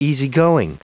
Prononciation du mot easygoing en anglais (fichier audio)
Prononciation du mot : easygoing